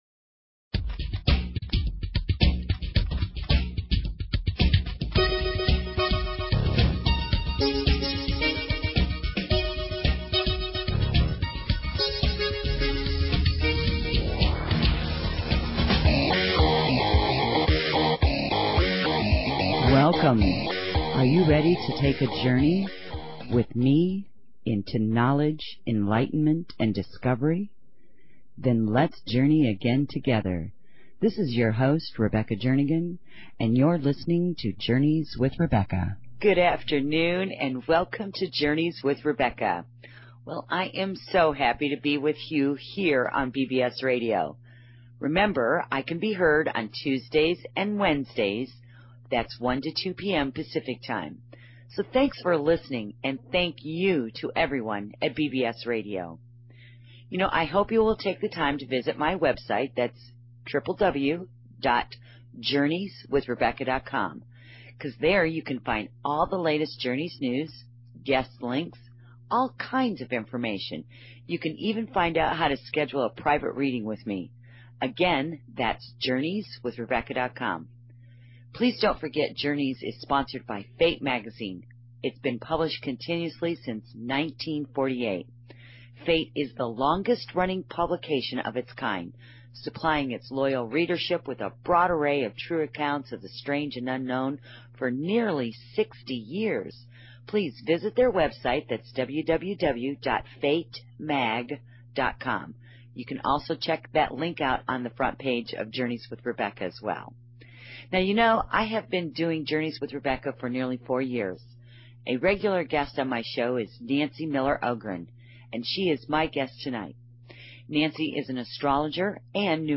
Talk Show Episode
Interview w